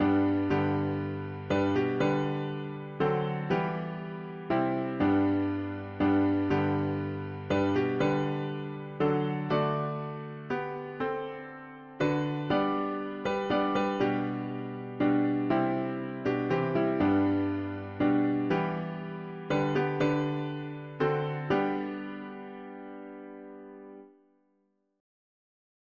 ‘Twas grace that taught… english christian 4part chords
American folk melody, 1831 Key: G major